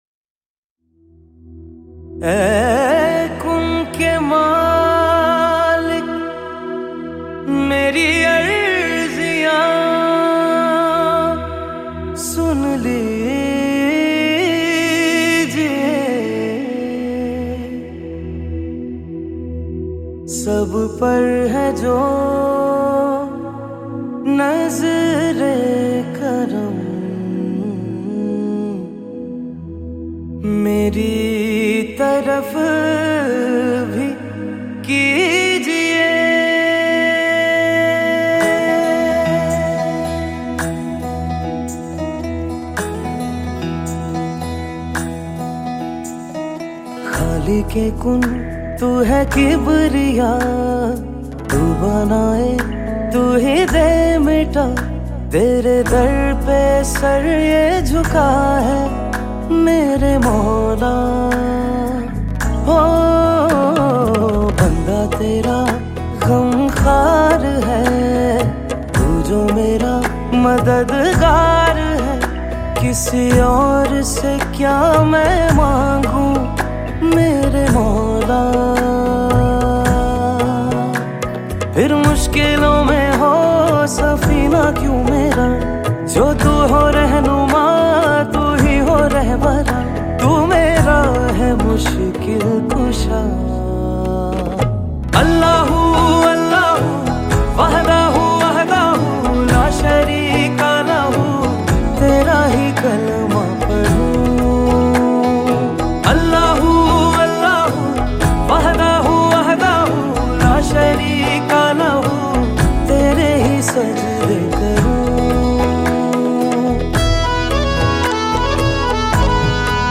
Category: Pakistani